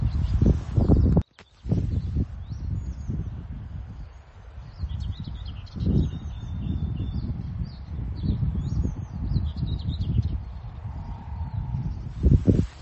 Птицы -> Славковые ->
дроздовидная камышевка, Acrocephalus arundinaceus
СтатусПоёт